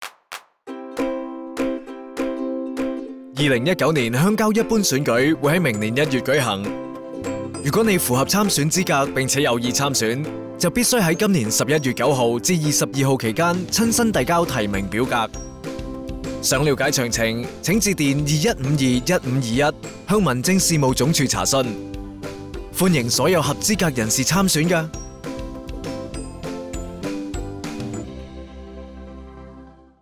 電台宣傳聲帶